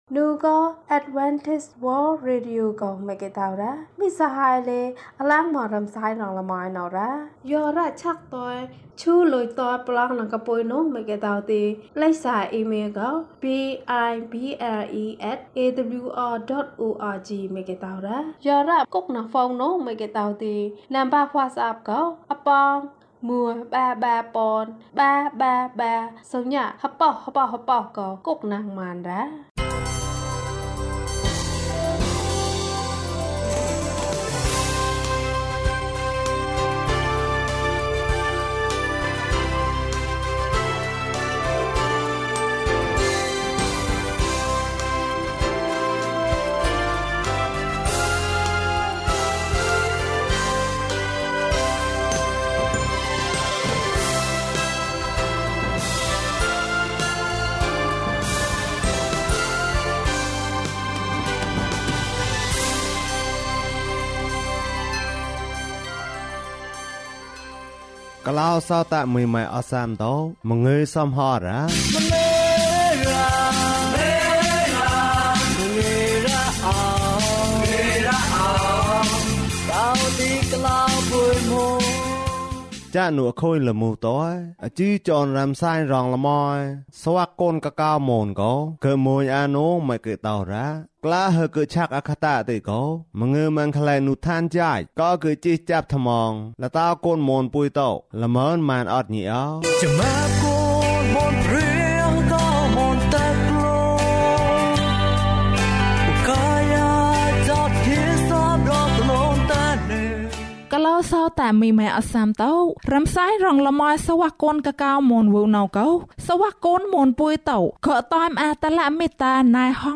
ဒုတိယ အကြိမ်။ ကျန်းမာခြင်းအကြောင်းအရာ။ ဓမ္မသီချင်း။ တရားဒေသနာ။